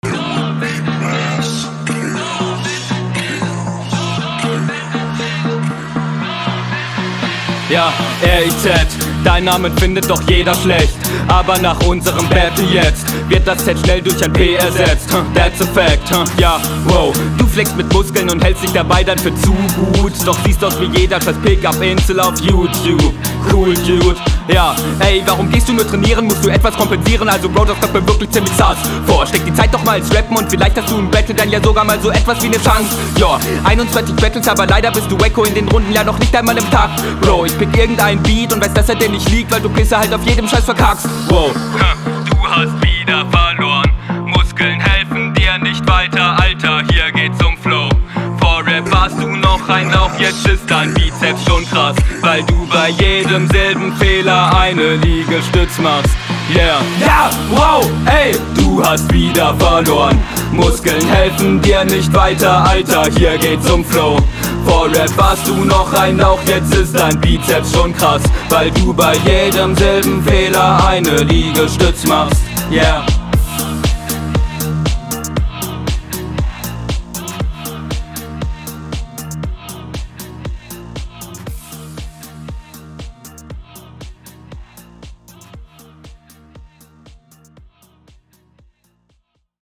find deine Stimme nicht so schön aufm Beat. bist zwar nice im Takt und deliverst …